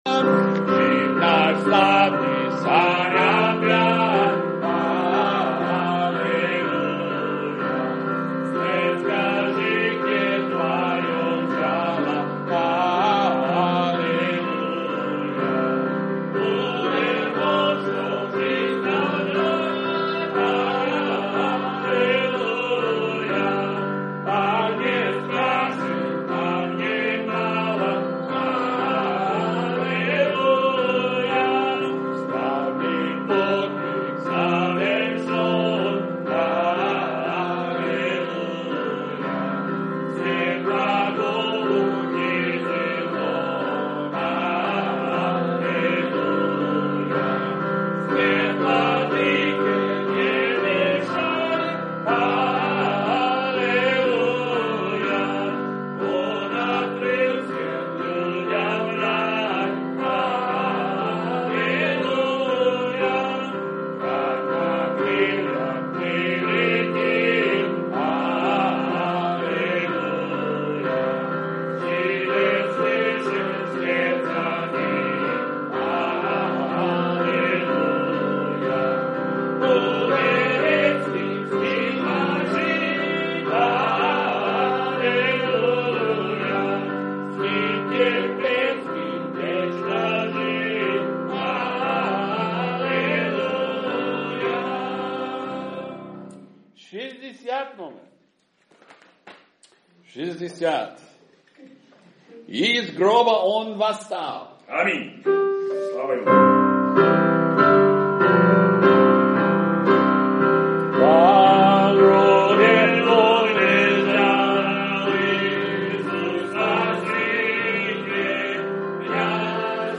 Audio Sermons
Preaching Russian 2019